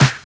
62 SNARE 5.wav